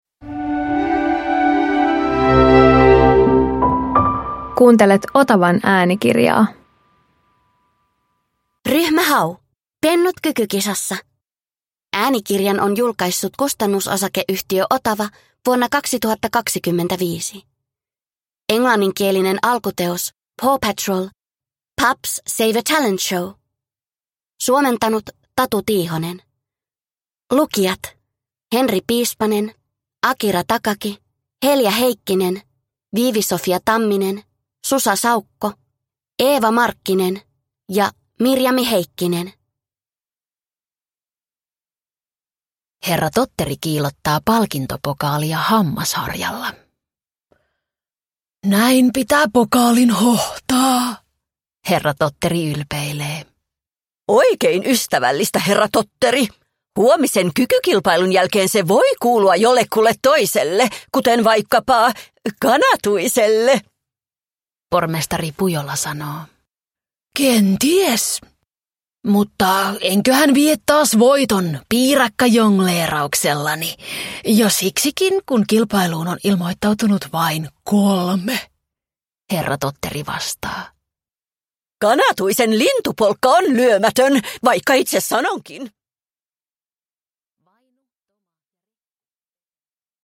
Ryhmä Hau - Pennut kykykisassa – Ljudbok